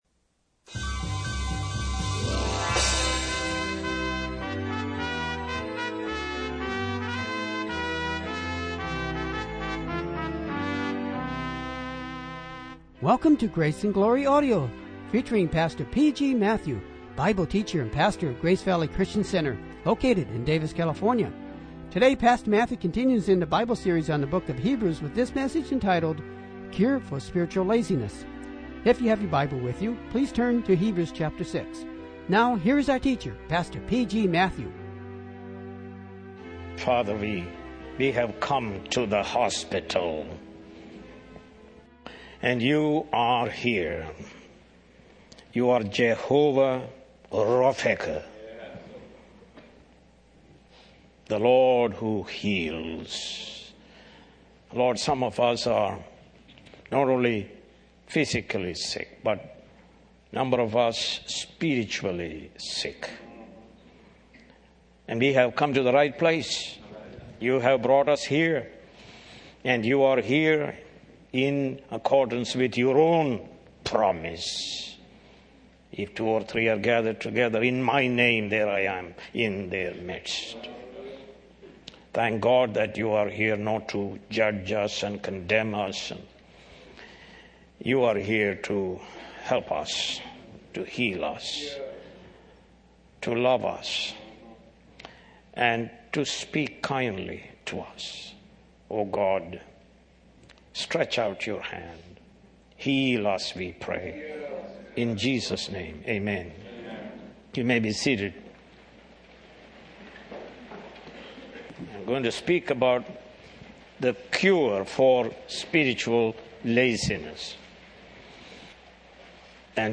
More Sermons From the book of Hebrews